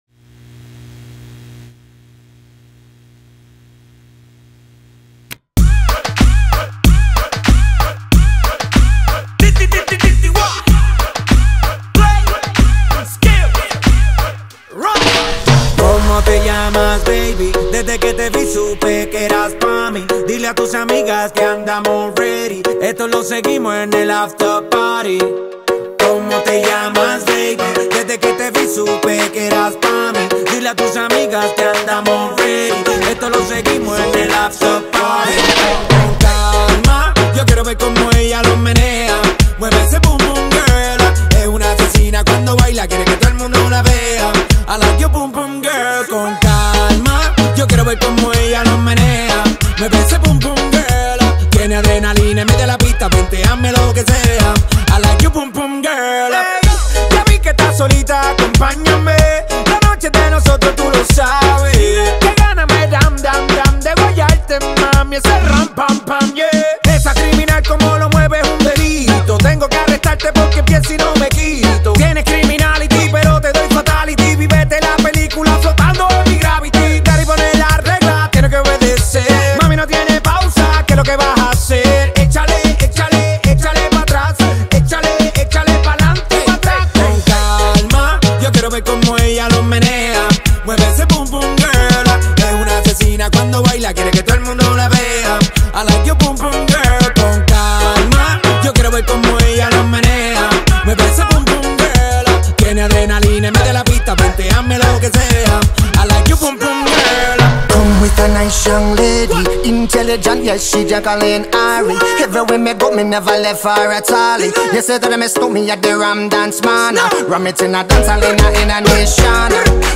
BPM94
Audio QualityCut From Video